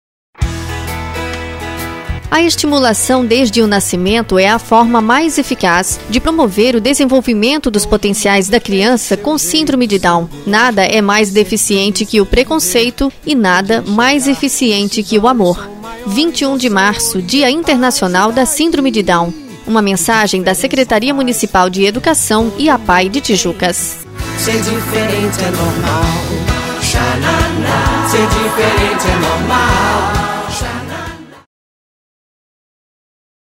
spot sindrome de down.mp3